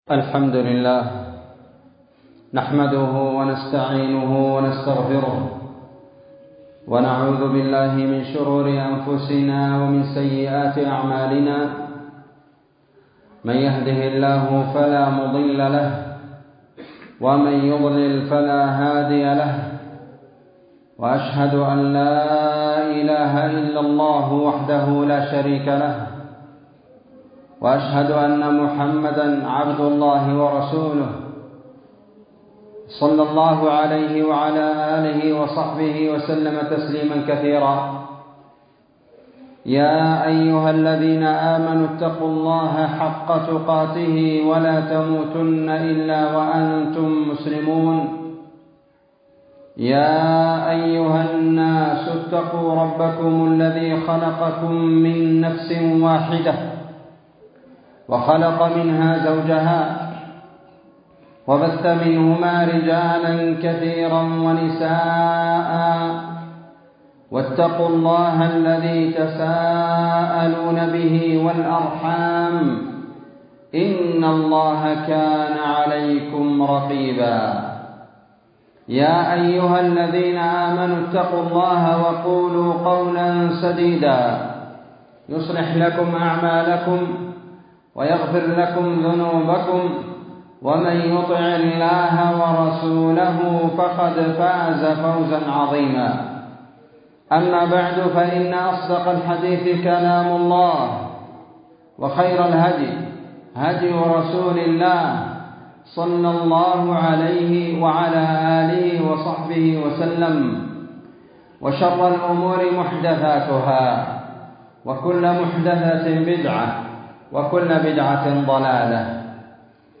خطبة بعنوان تفسير سورة قريش وبيان عظم نعمة الأمن والكفاف 20 جمادى الآخرة 1444
مدينة شيخ- بلاد أرض الصومال